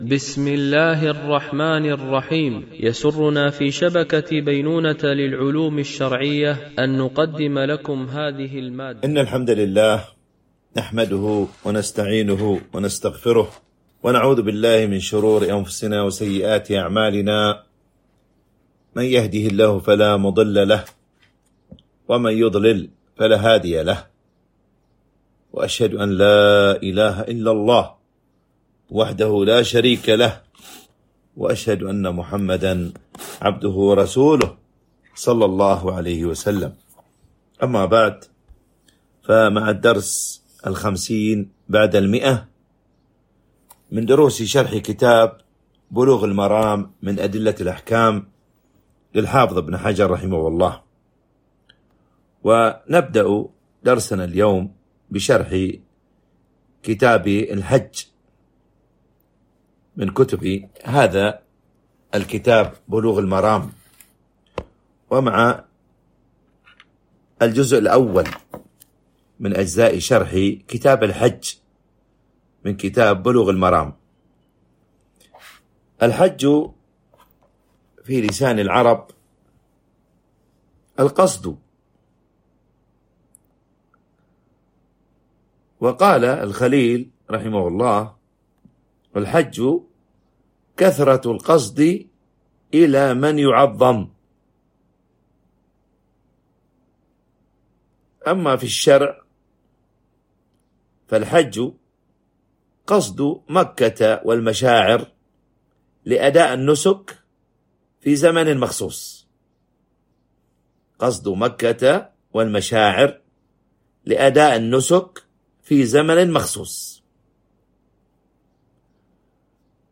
شرح بلوغ المرام من أدلة الأحكام - الدرس 150 ( كتاب الحج - الجزء الأول - الحديث 708 - 713 )